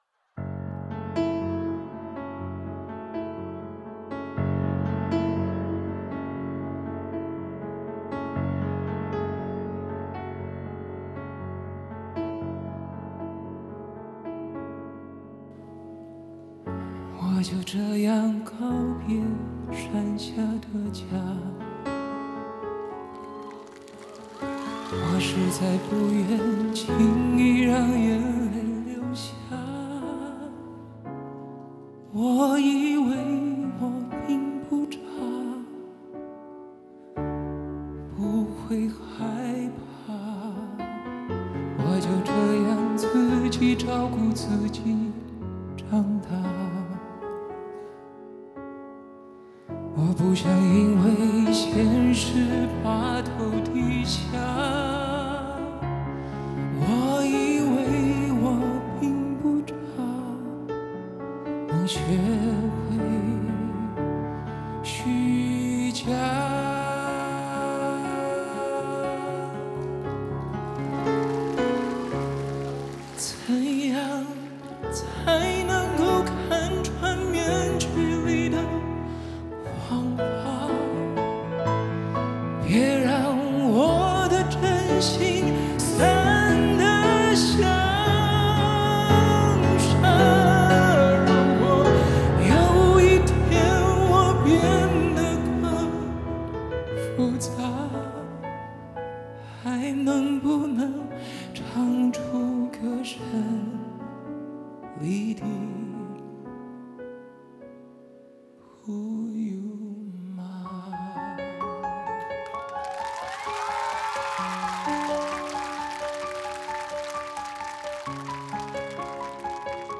Ps：在线试听为压缩音质节选，体验无损音质请下载完整版 编曲